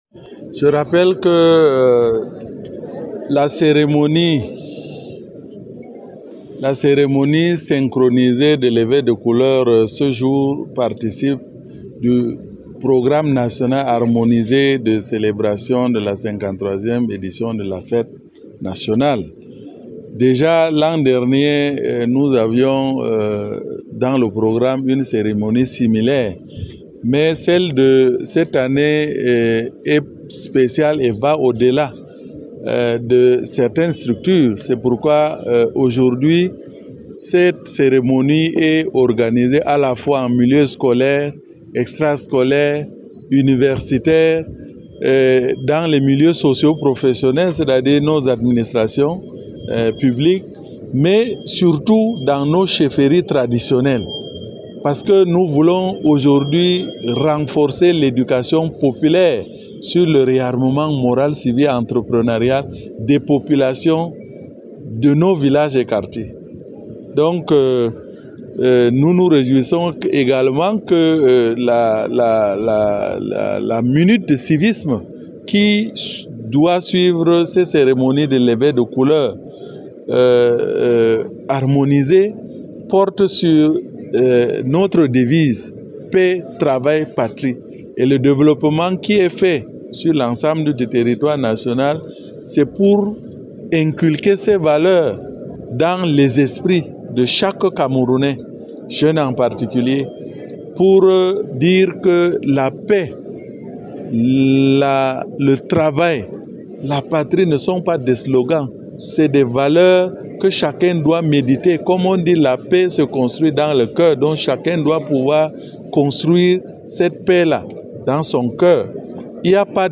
🎙Interview de Mounouna Foutsou, Ministre de la Jeunesse et de l’Education civique👇🏽